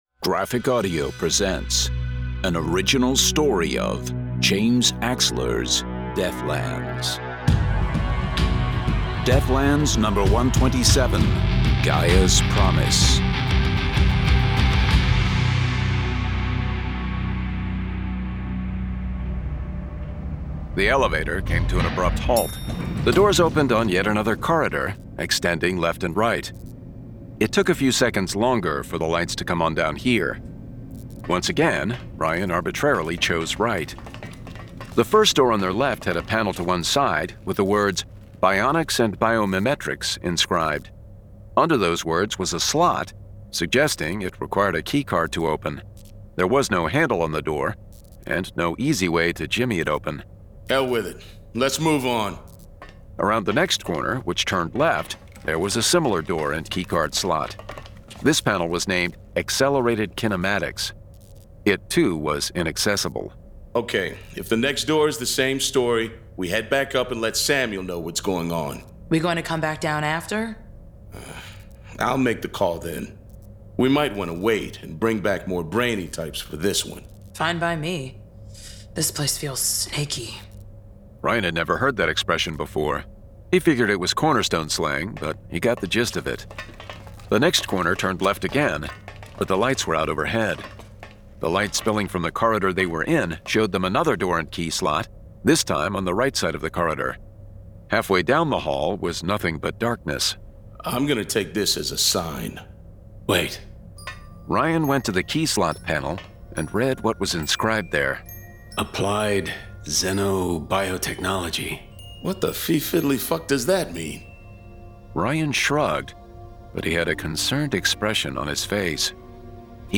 Deathlands 127: Gaia's Promise [Dramatized Adaptation]
Full Cast. Cinematic Music. Sound Effects.
Genre: Science Fiction